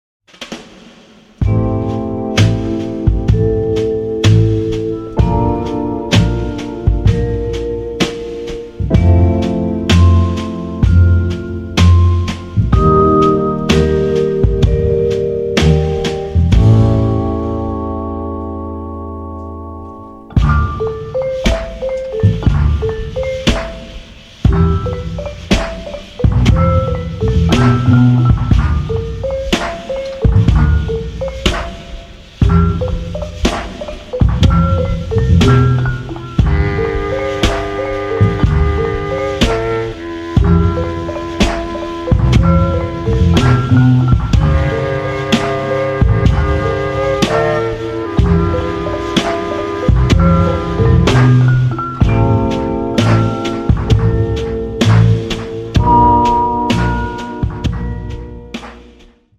Instrumental funk heroes